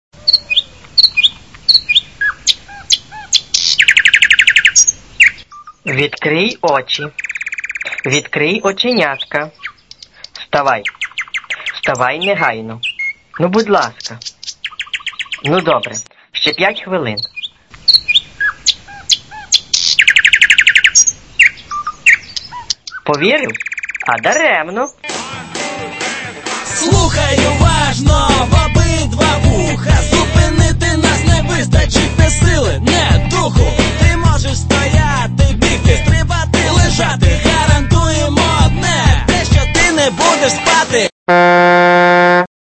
Тип: рінгтони